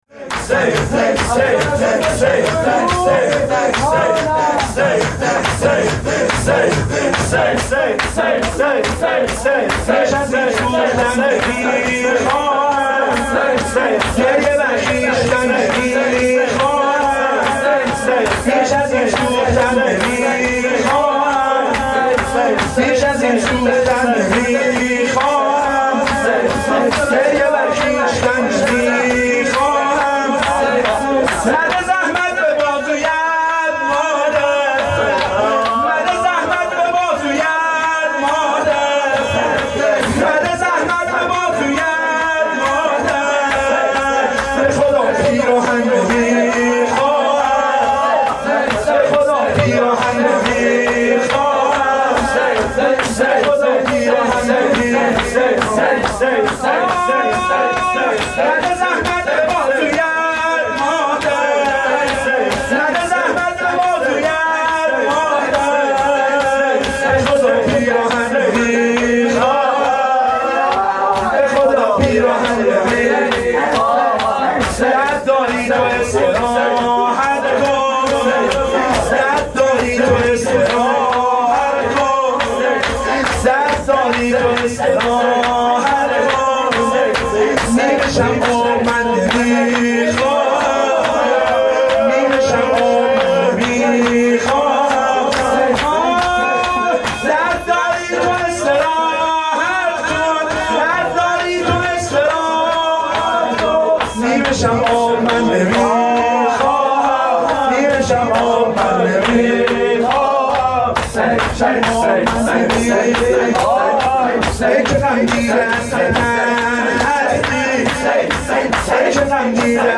حسینیه, هیئت رایت الهدی کمالشهر
نوای فاطمیه
مداحی فاطمیه